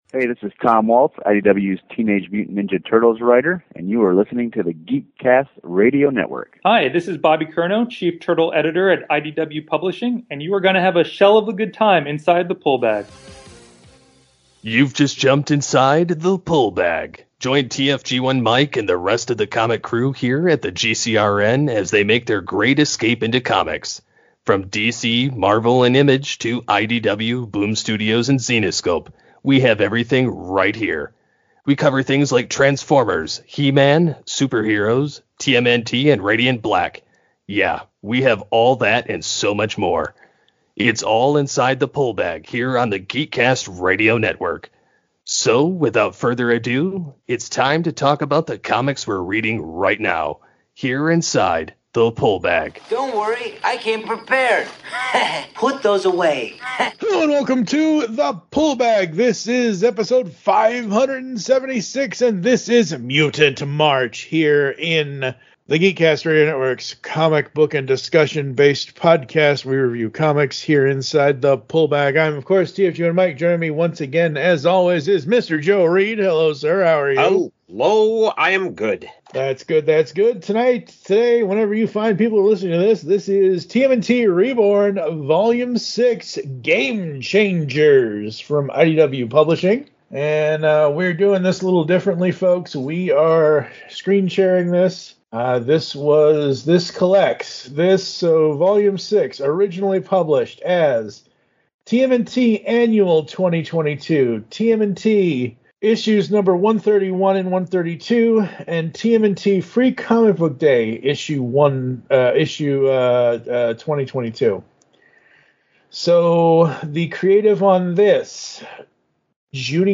It's your favorite comic shop conversations delivered right to your ears!